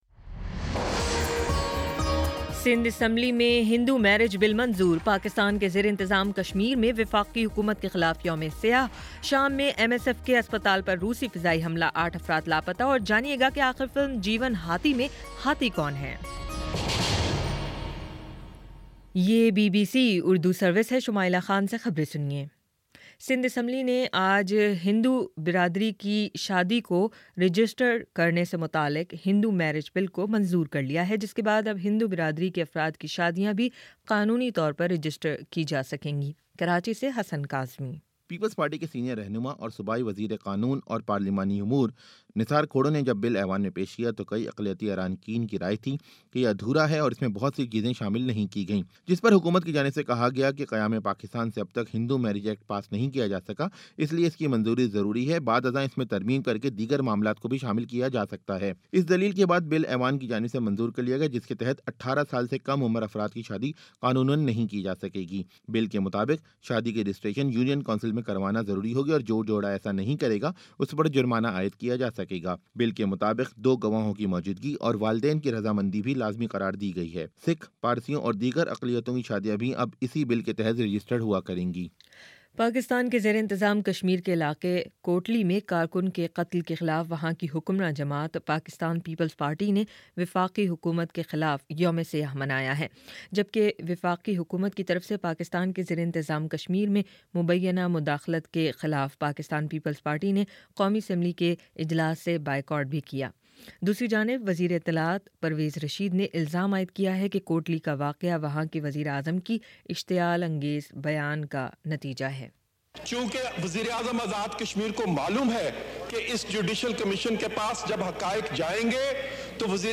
فروری 15: شام سات بجے کا نیوز بُلیٹن